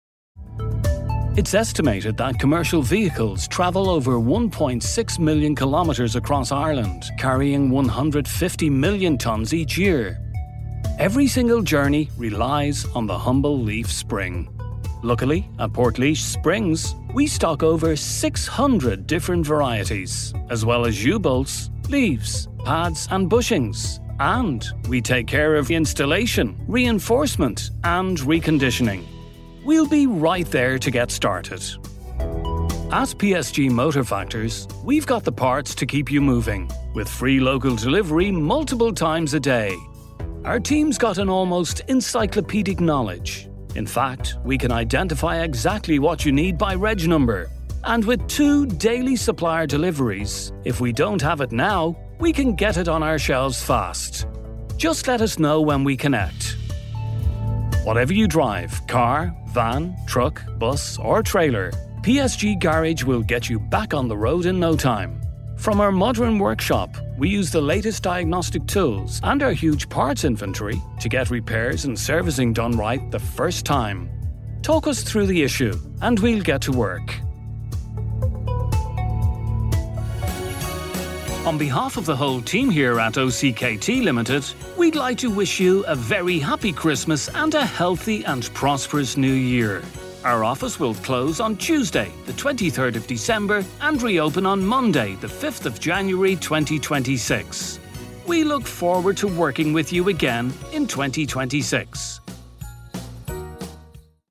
IVR
My accent is neutral english and my voice is fresh, warm, engaging and believable.
Soundproof studio and booth